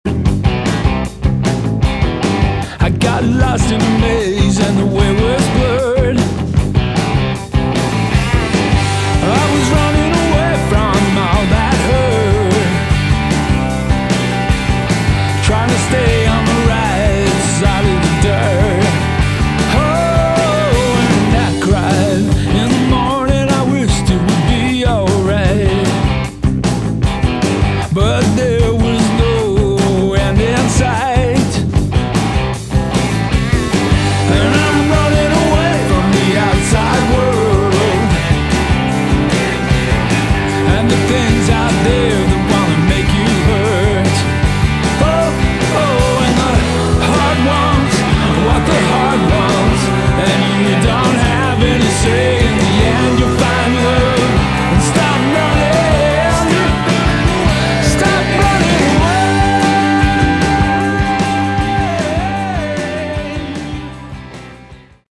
Category: Hard Rock
lead & harmony vocals, electric guitar
harmony & background vocals
drums
electric bass guitar